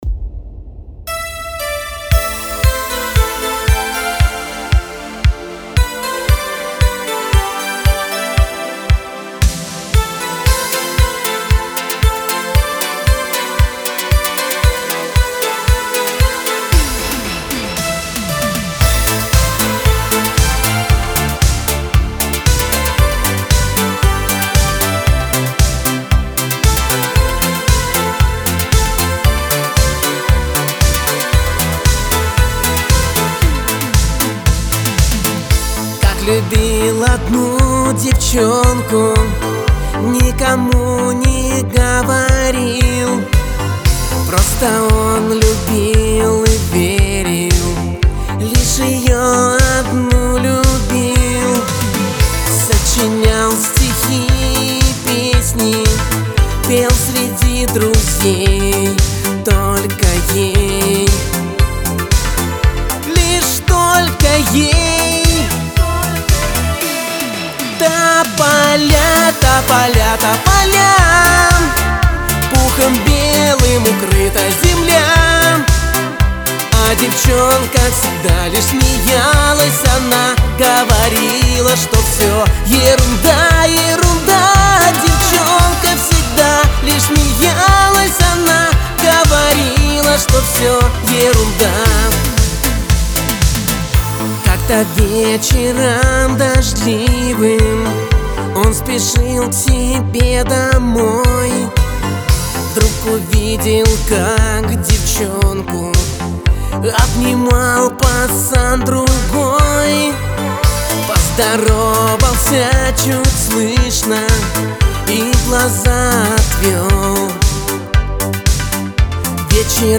диско